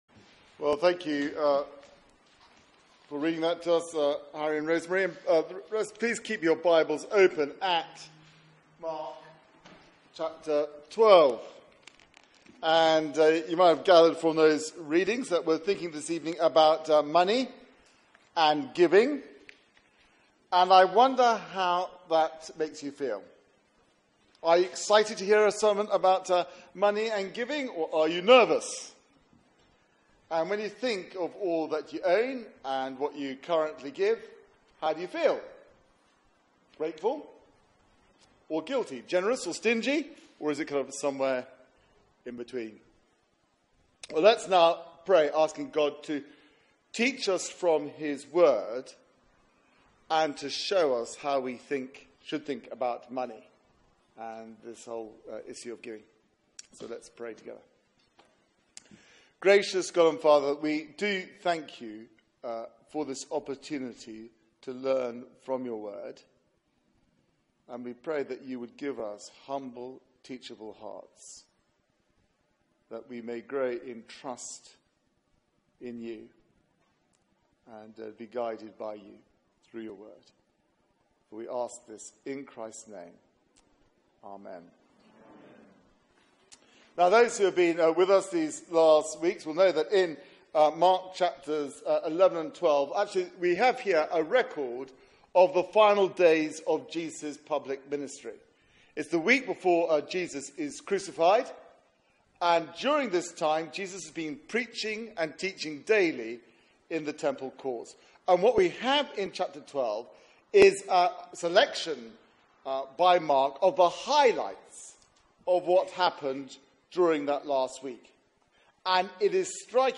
Media for 6:30pm Service on Sun 18th Jun 2017 18:30 Speaker
Theme: Genuine Devotion Sermon